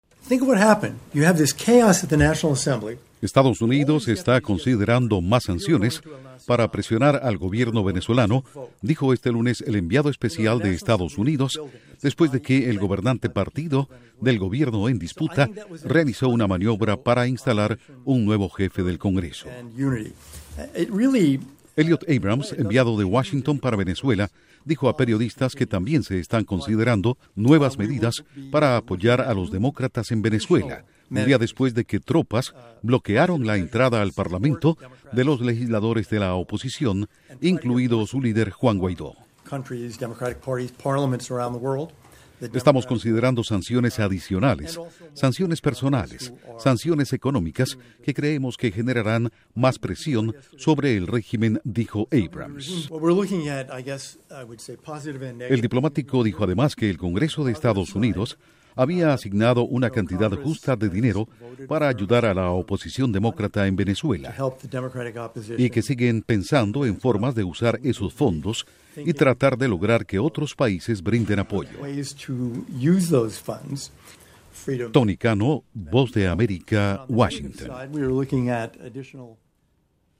Duración: 1:20 Con declaraciones de Elliot Abrams/Enviadp especial EE.UU. para Venezuela